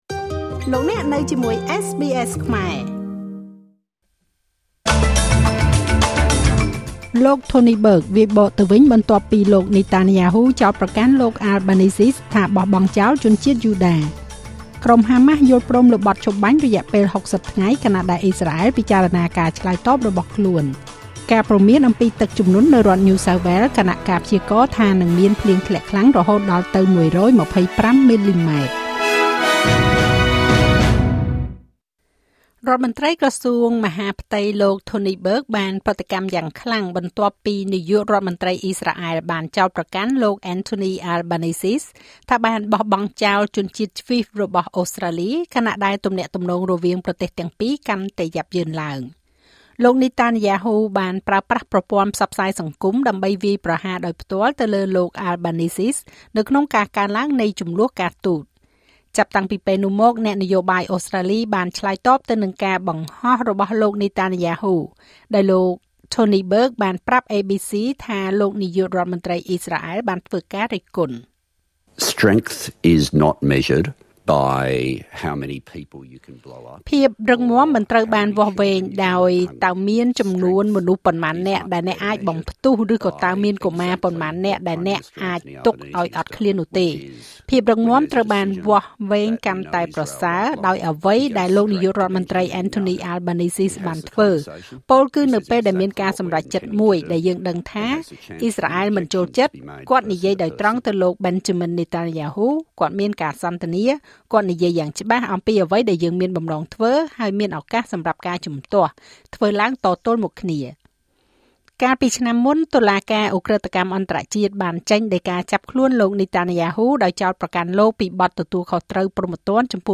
នាទីព័ត៌មានរបស់SBSខ្មែរ សម្រាប់ថ្ងៃពុធ ទី២០ ខែសីហា ឆ្នាំ២០២៥